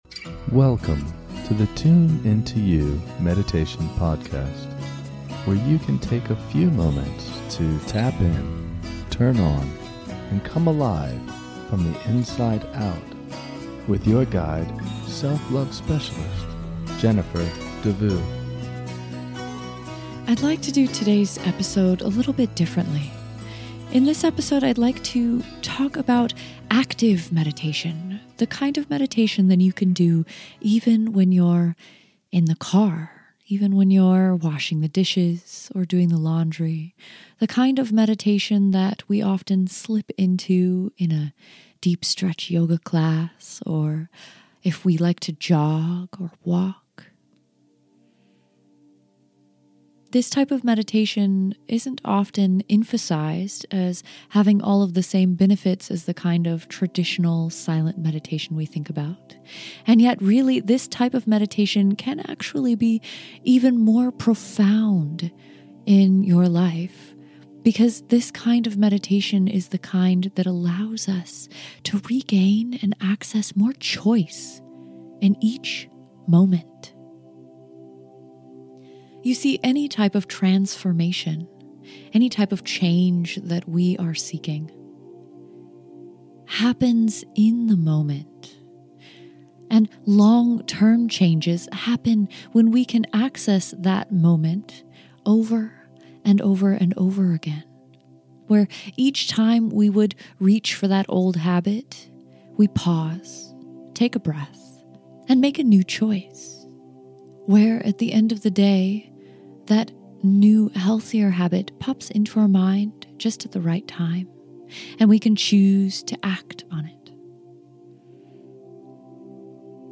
Active Mindfulness Meditation